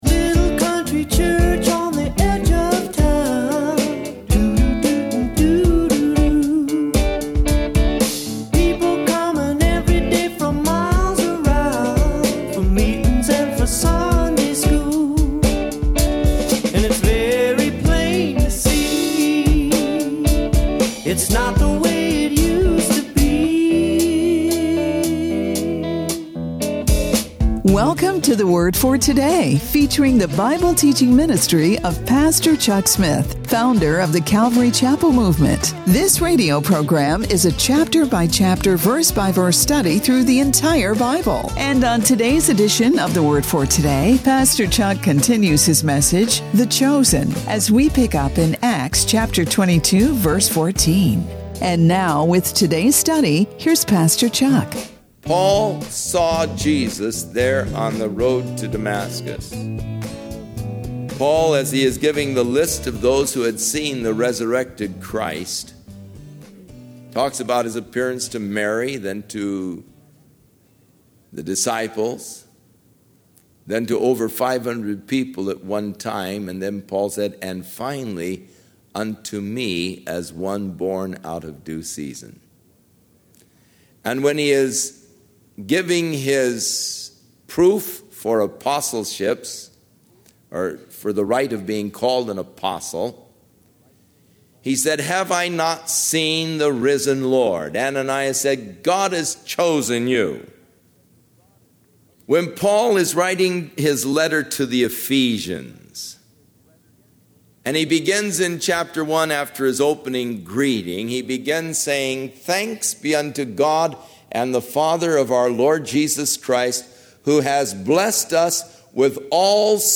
This radio program is a chapter-by-ch chapter verse by verse study through the entire Bible.